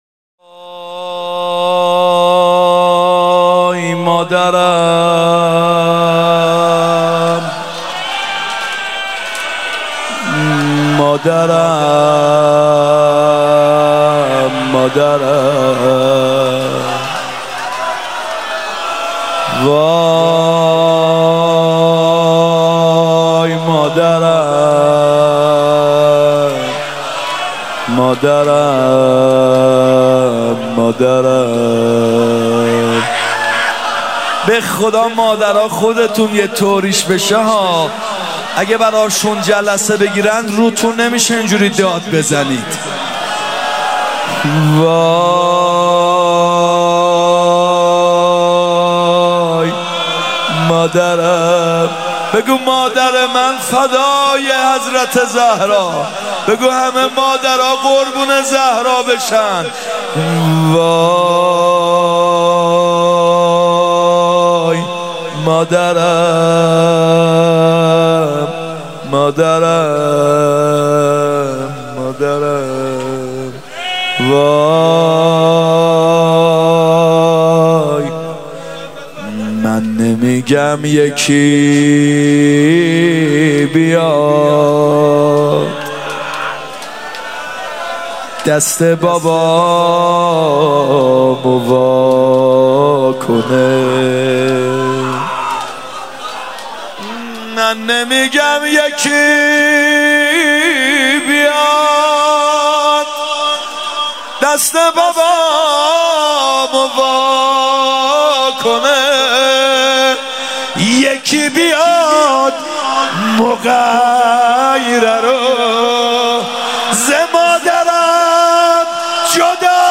محرم 96 روضه